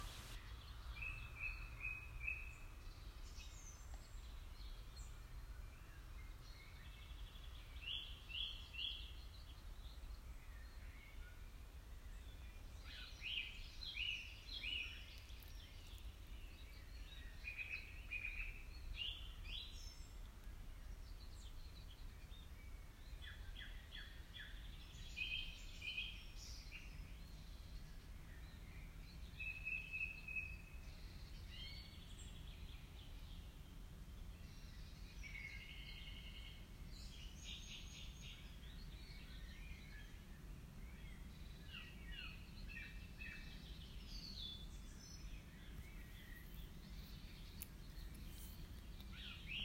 ’s Ochtends opstaan bij het geluid van de vele vogels, die een plekje hebben gevonden op ons terrein en in het omliggende bos, is een heel bijzondere ervaring.